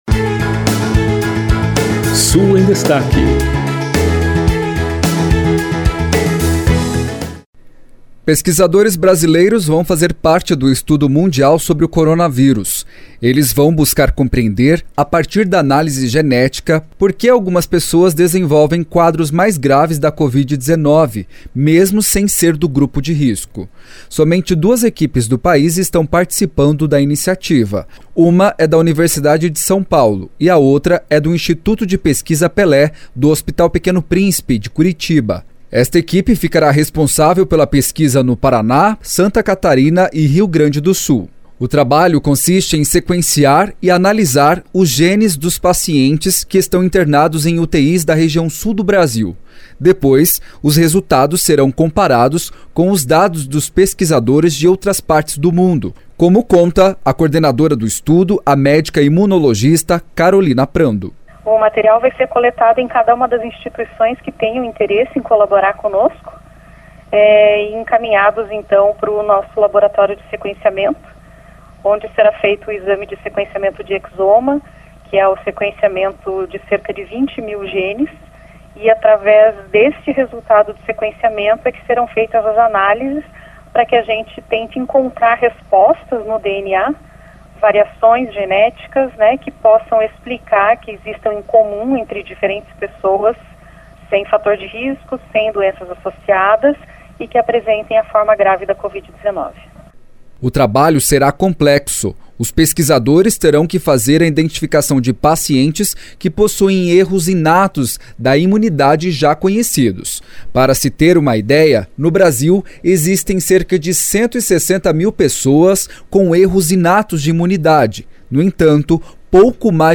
De Curitiba, repórter